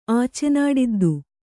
♪ ācenāḍiddu